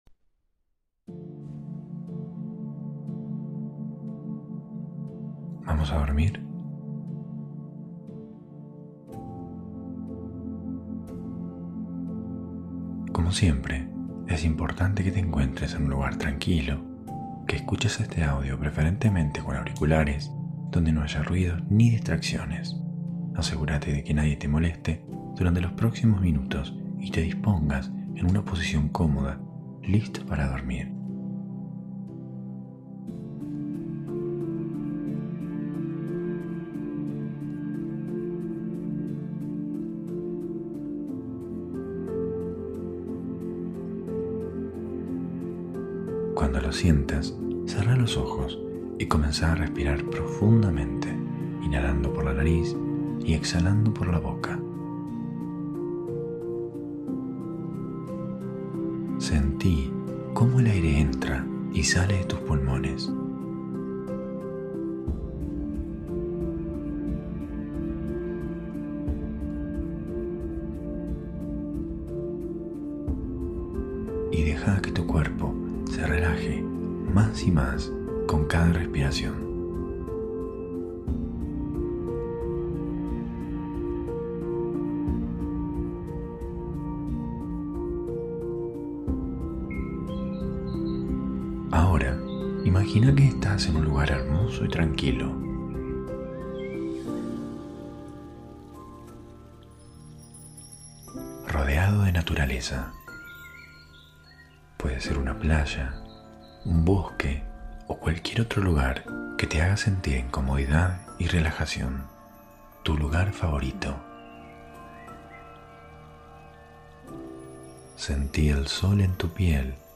Hipnosis guiada para dormir. [Altamente recomendable escucharlo con auriculares ] Hosted on Acast.